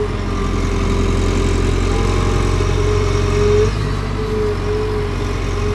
CONSTRUCTION_Digger_Digging_02_loop_stereo.wav